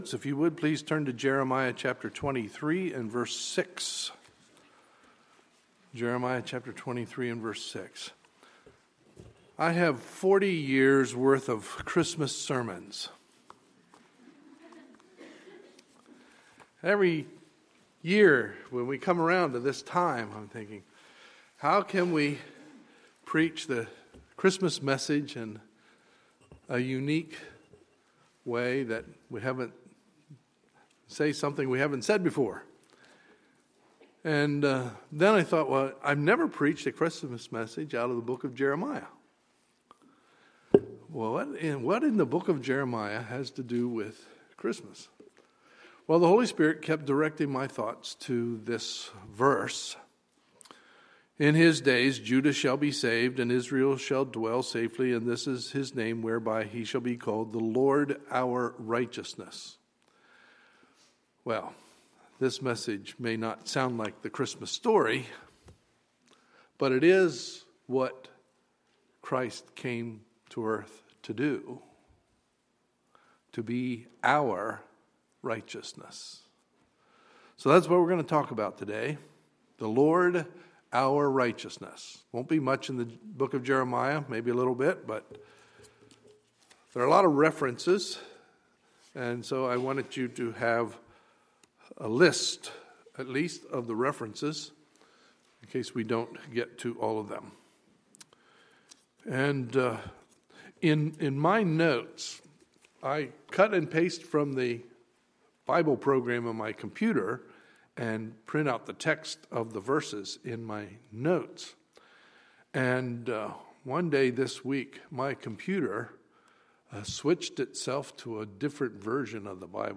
Sunday, December 13, 2015 – Sunday Morning Service